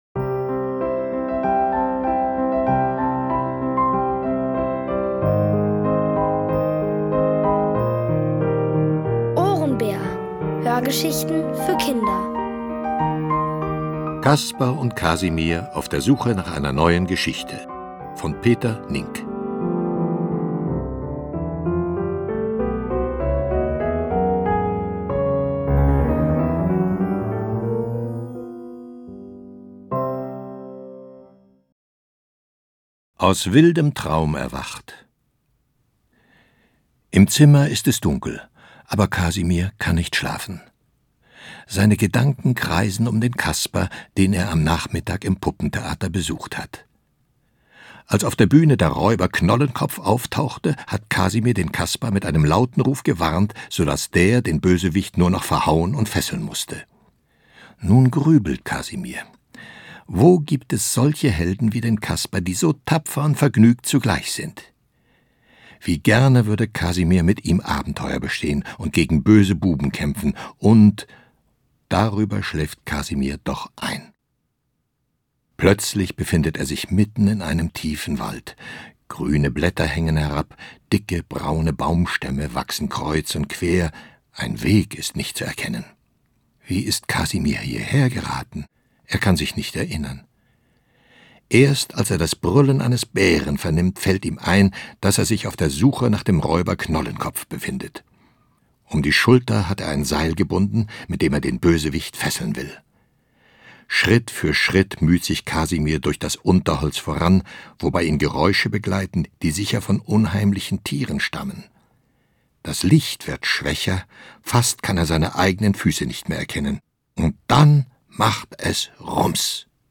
Von Autoren extra für die Reihe geschrieben und von bekannten Schauspielern gelesen.
Es liest: Otto Mellies.